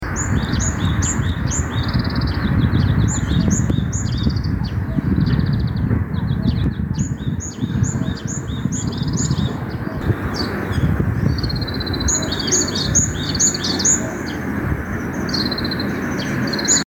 병아리 처럼, 여러번, 삐악삐악삐악 계속 우는 새가 있습니다. (1초~10초까지) 2. 11초부터는 마치 여치처럼 찌르륵 우는 새 소리가 두번 들려요 혹시 아시는지요 ?